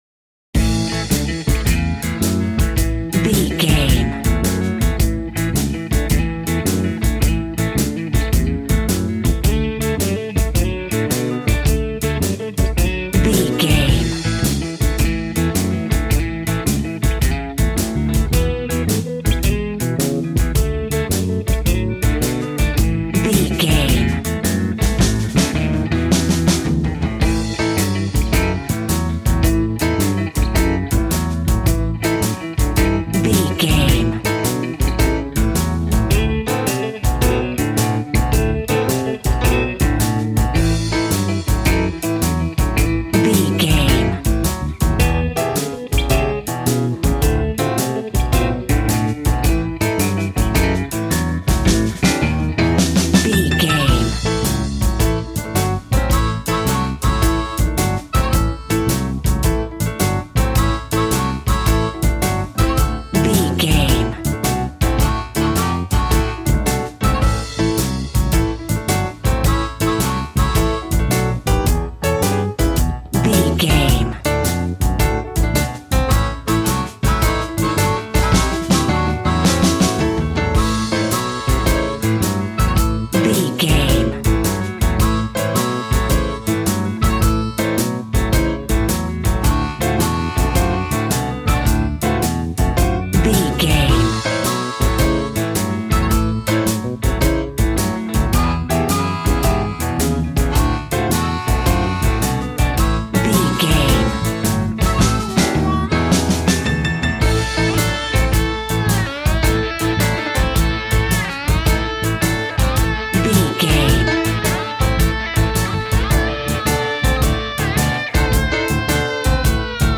Ionian/Major
sad
mournful
bass guitar
electric guitar
electric organ
drums